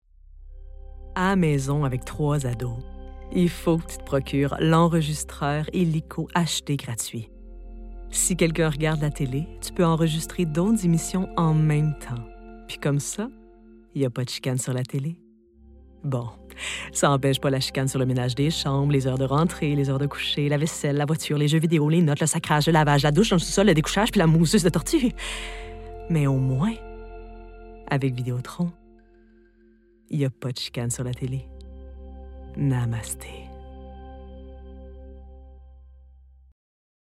Timbre Grave
Vidéotron - Zen - Humoristique - Québécois naturel /
Annonceuse - Fictif 2022 00:37 789 Ko